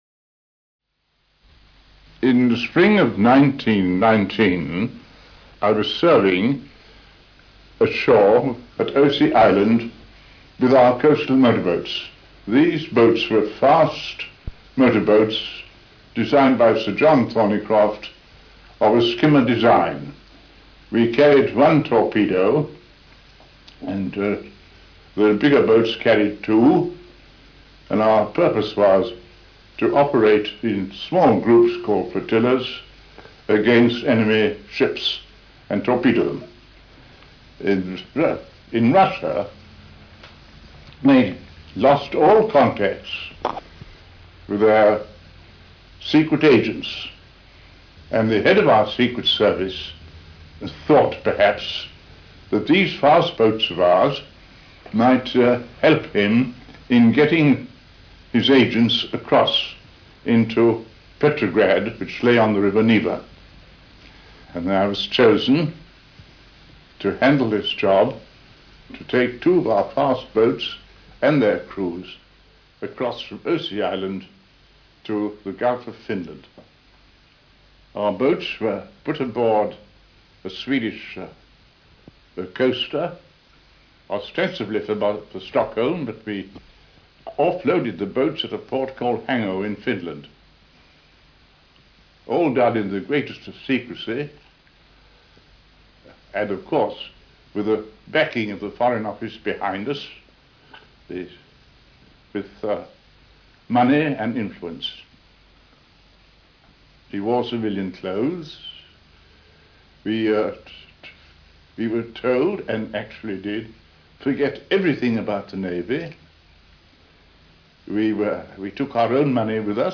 Click here to listen to Augustus Agar VC describing the heroic exploits that won him the VC TOP